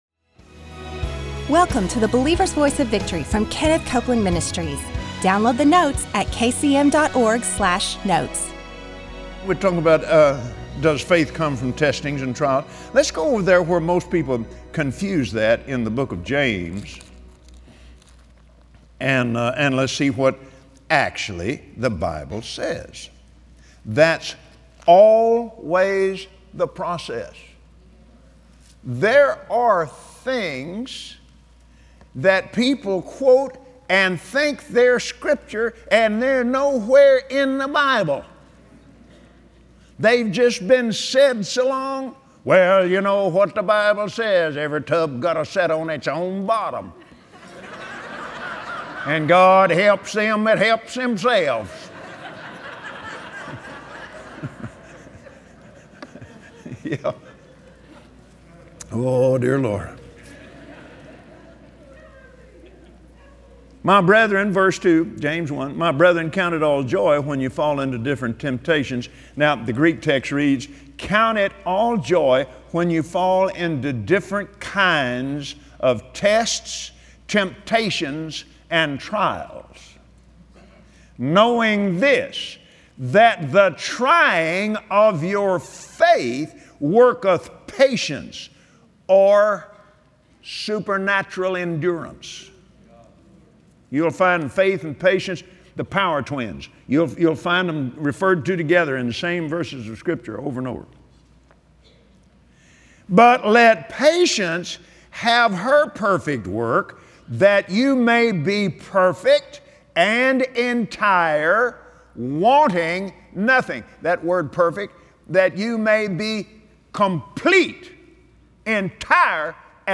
Join Kenneth Copeland today, on the Believer’s Voice of Victory, as he explains how faith is released. Learn the connection between the truth of God’s Word and living by faith.